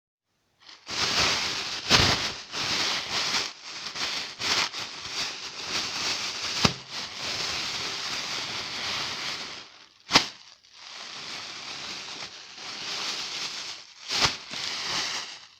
Звуки целлофана
Шуршание целлофанового пакета звук